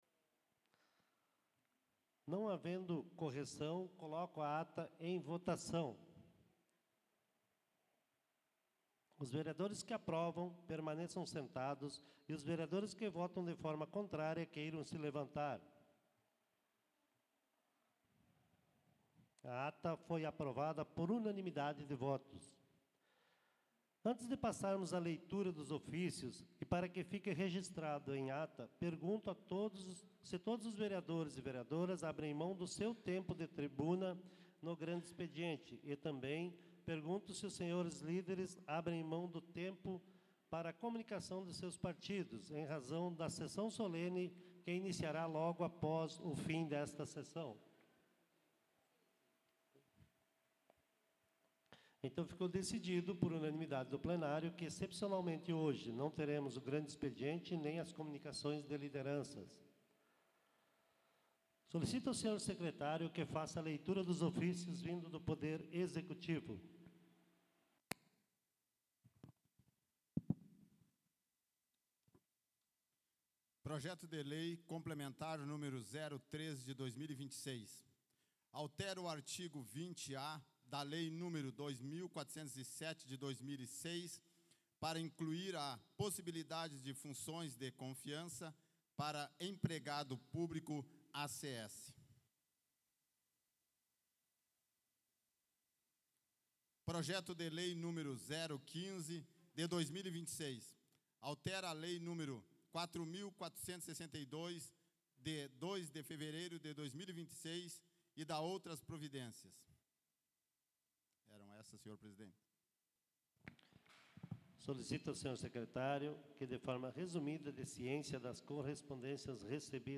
Áudio Sessão 31.03.2026 — Câmara de Vereadores